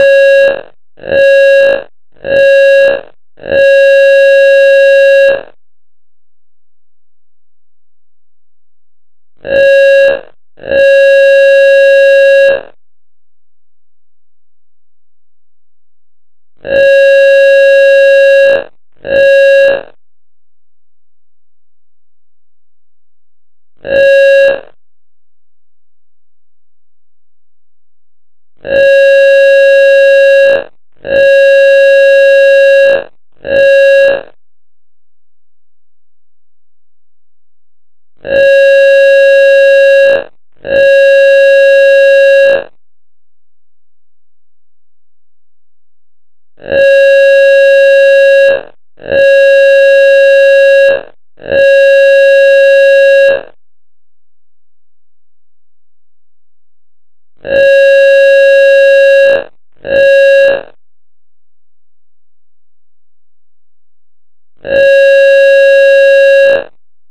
morsecode speurtocht 29-6-22
morsecode-speurtocht-29-6-22.mp3